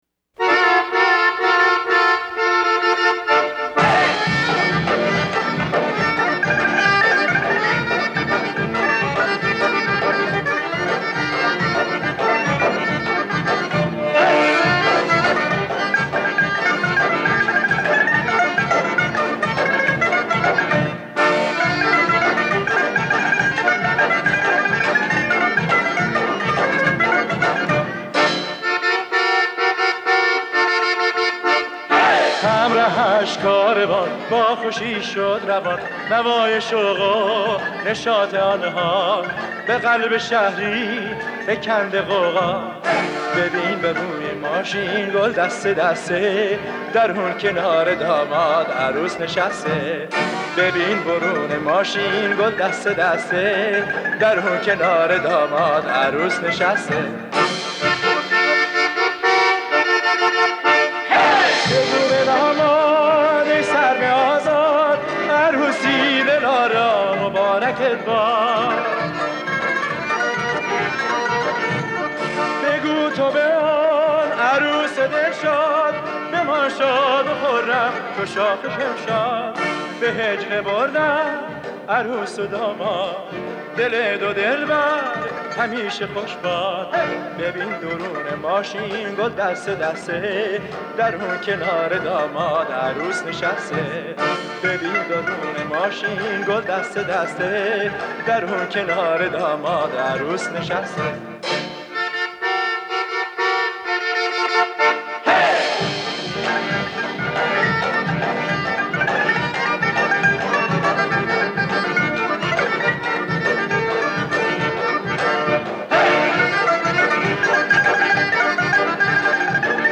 در مایه اصفهان